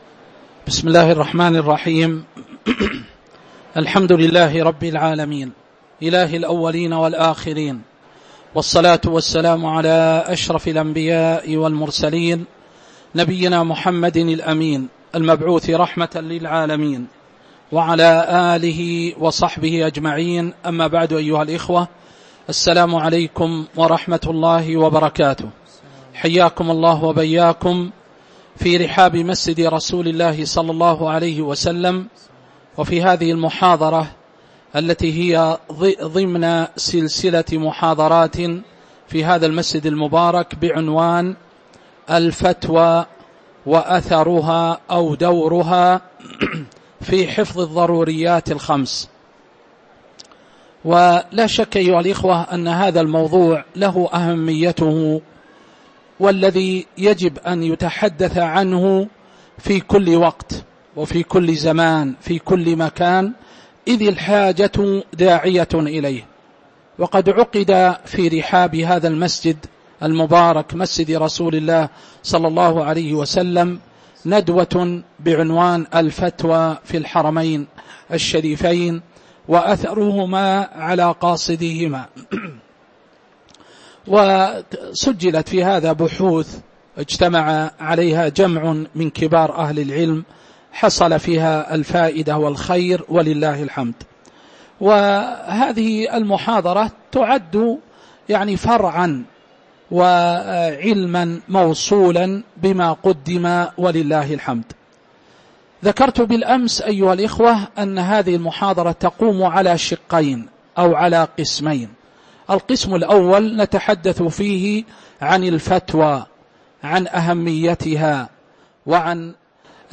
تاريخ النشر ١٦ جمادى الأولى ١٤٤٦ هـ المكان: المسجد النبوي الشيخ